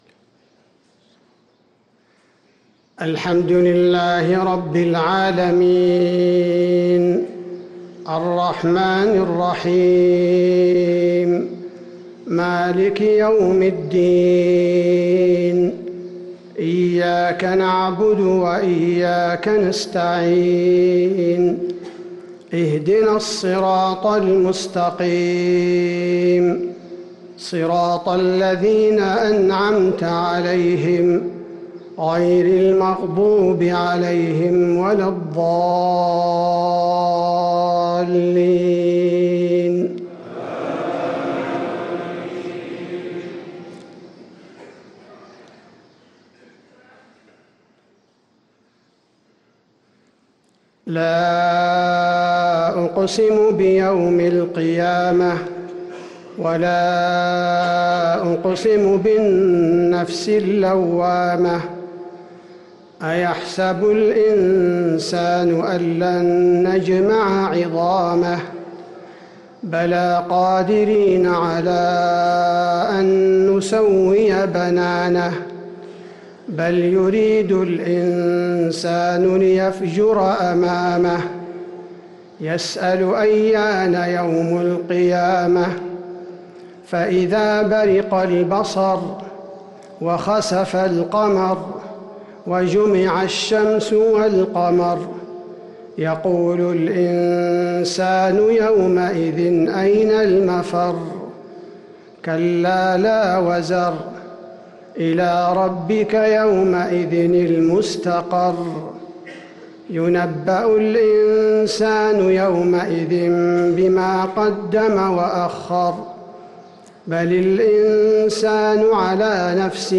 صلاة الفجر للقارئ عبدالباري الثبيتي 26 رمضان 1444 هـ
تِلَاوَات الْحَرَمَيْن .